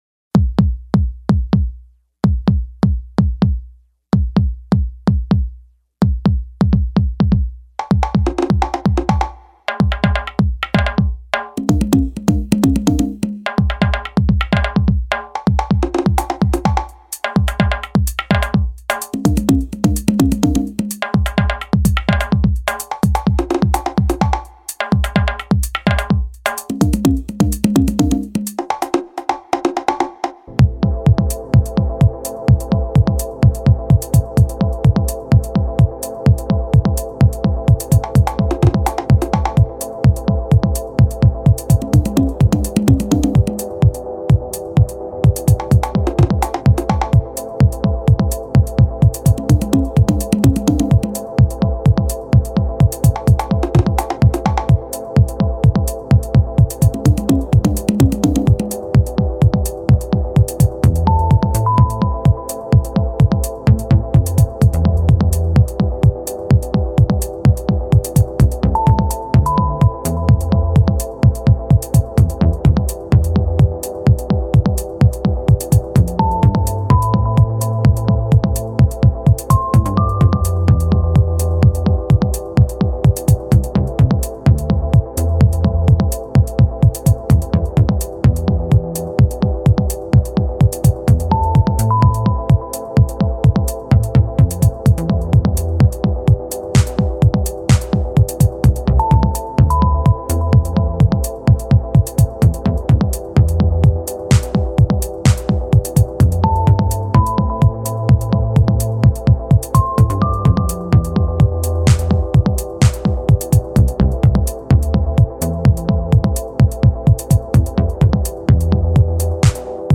микс в стиле progressive house